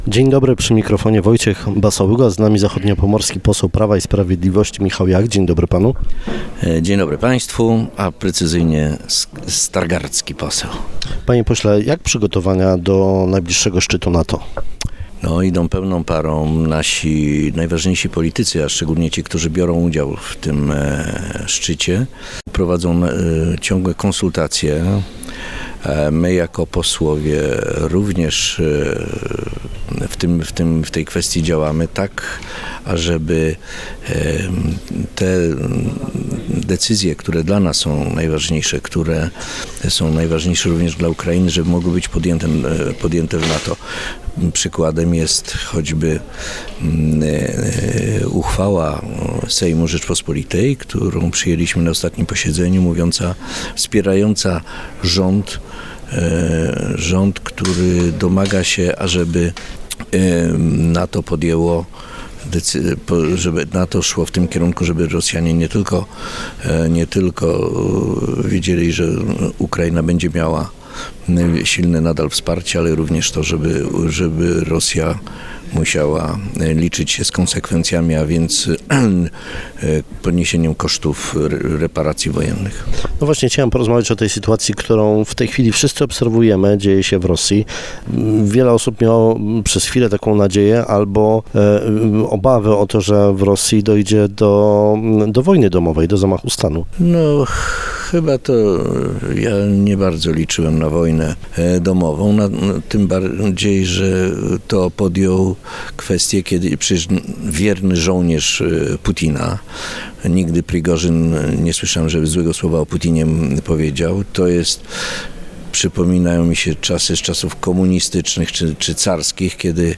– System umożliwia zwalczanie wielu celów jednocześnie – mówi zachodniopomorski poseł PiS Michał Jach, szef sejmowej Komisji Obrony Narodowej.